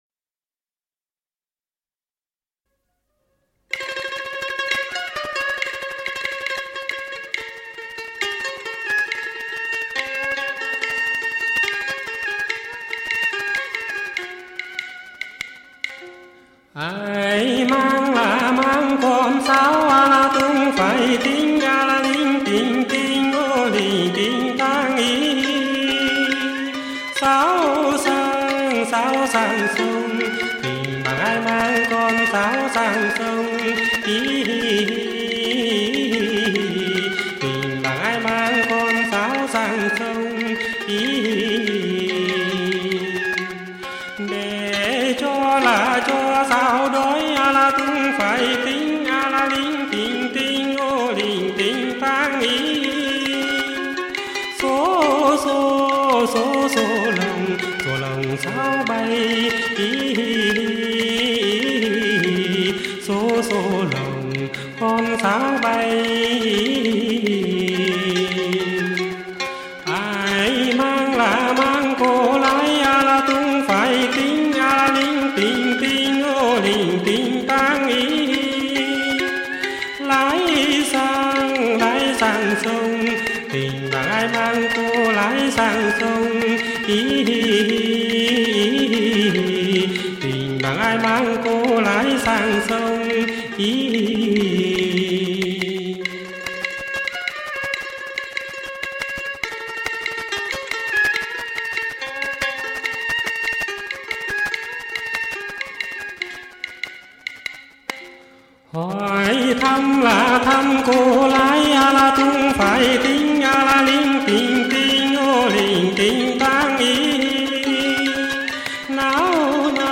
Các quãng âm giữ y nguyên cao độ của mình.